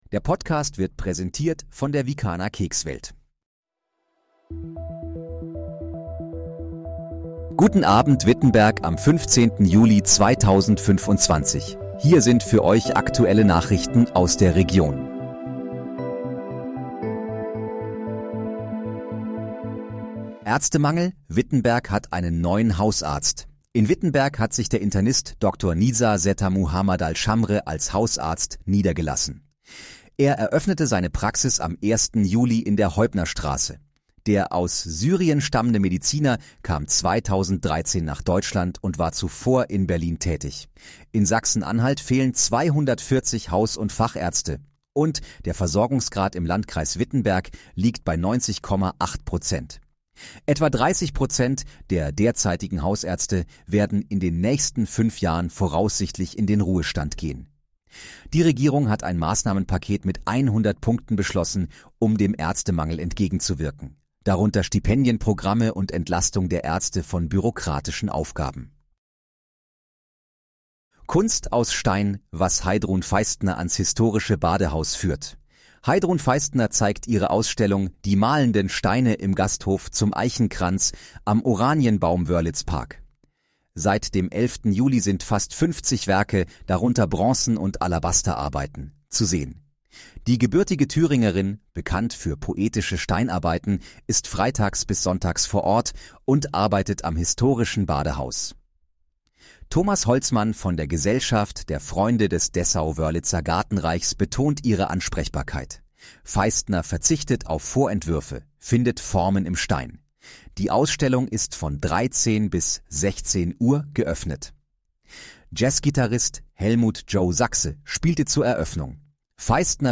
Guten Abend, Wittenberg: Aktuelle Nachrichten vom 15.07.2025, erstellt mit KI-Unterstützung
Nachrichten